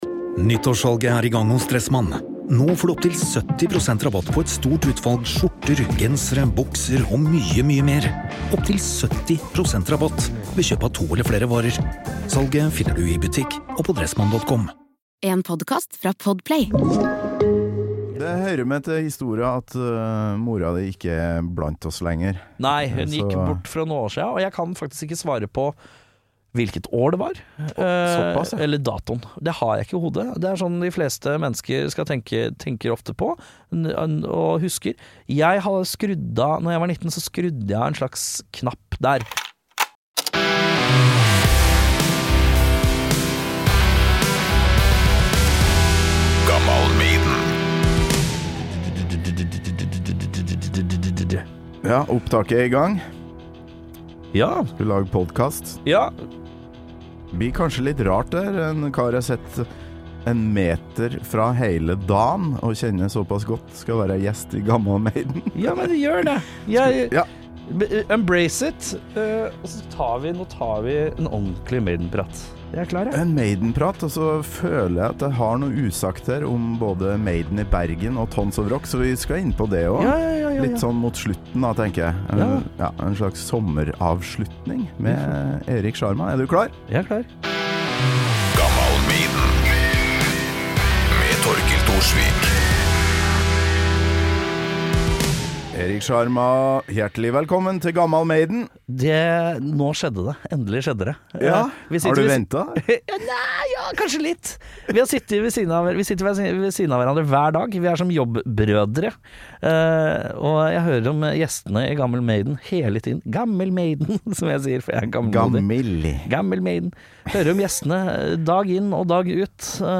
Så nå har han tatt til vett og er her og babler om Maiden i stedet. Det blir allsang, det blir Rock in Rio, det blir alvorsprat om en oppvekst utenom det vanlige og det blir en bitteliten recap på hva vi har opplevd i det siste på Maiden i Bergen og på Tons of Rock i Oslo.